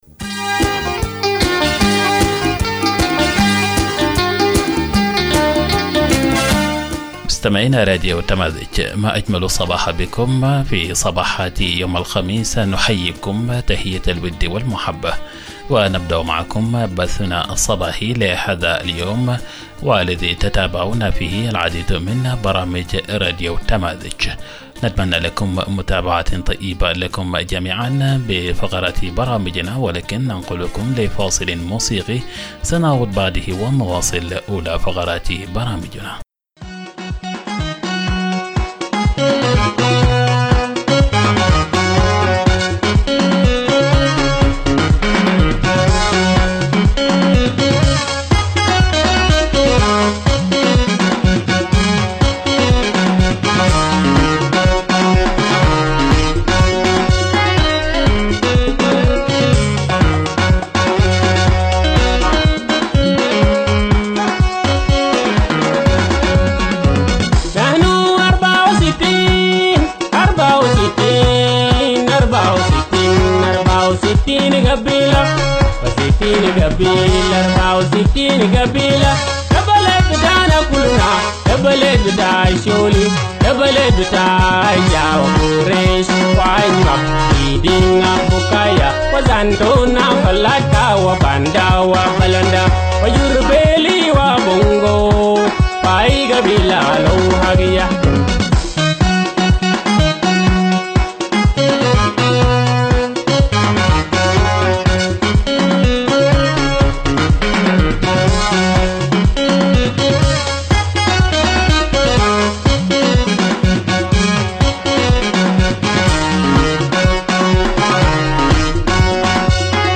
Morning Broadcast 04 December - Radio Tamazuj